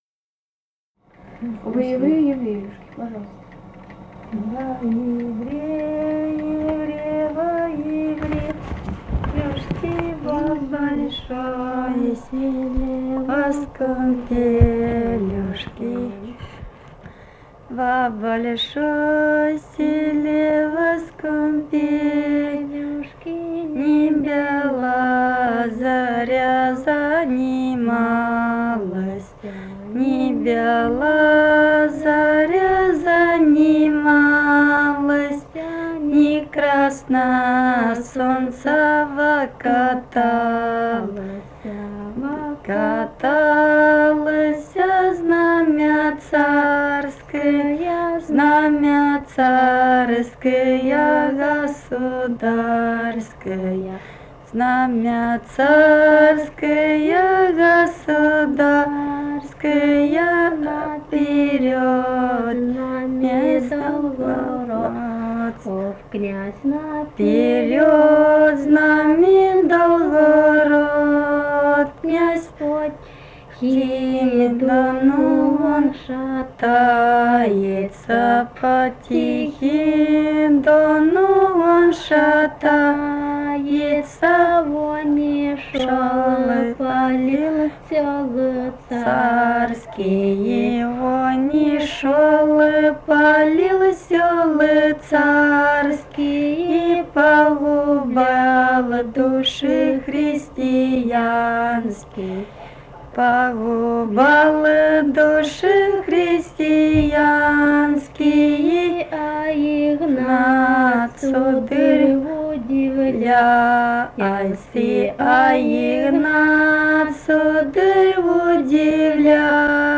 Этномузыкологические исследования и полевые материалы
«Во яре, яре, во яреюшке» (историческая «об Игнате»).
Ставропольский край, пос. Новокумский Левокумского района, 1963 г. И0726-09